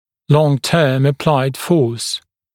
[lɔŋ’tɜːm ə’plaɪd fɔːs][лон’тё:м э’плайд фо:с]длительно прилагаемая сила; длительное приложение силы